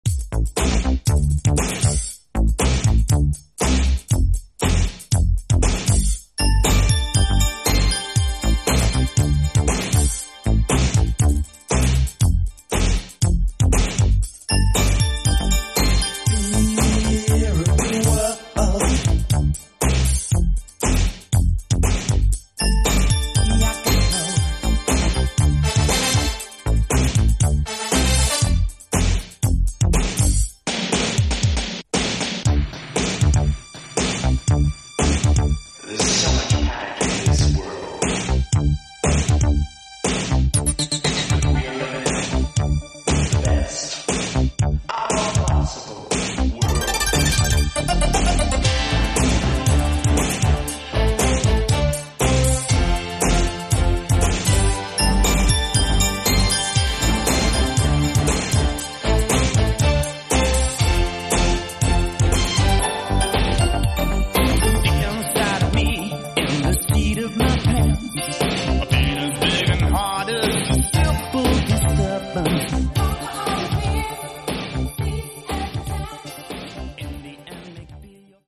format: 5" compilation
mixed & remixed by various DJs